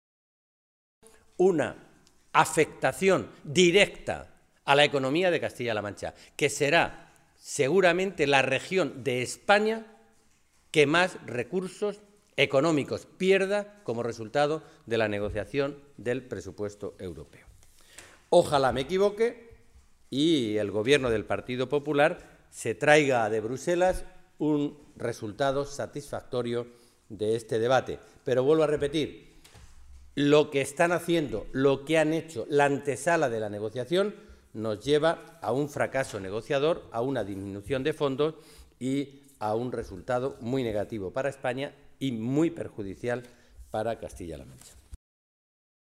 Alejandro Alonso, diputado nacional del PSOE de Castilla-La Mancha
Cortes de audio de la rueda de prensa